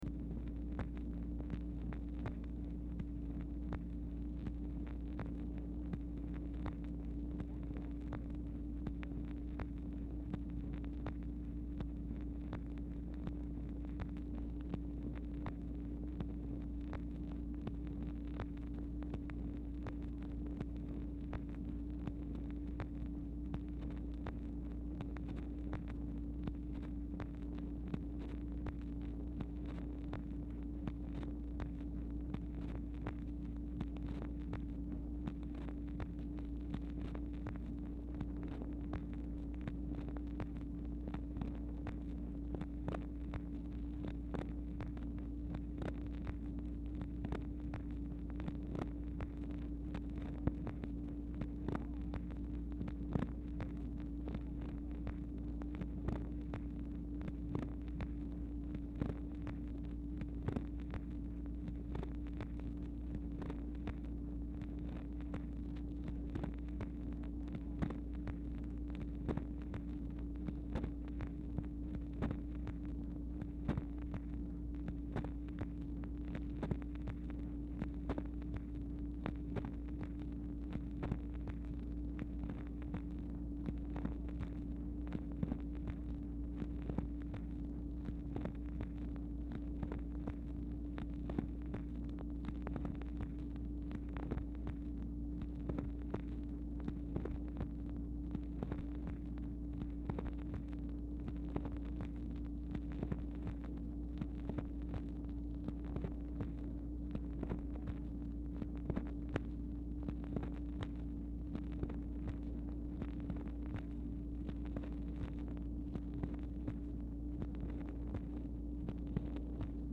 Telephone conversation # 3741, sound recording, OFFICE NOISE, 6/15/1964, time unknown | Discover LBJ
Format Dictation belt
Location Of Speaker 1 Oval Office or unknown location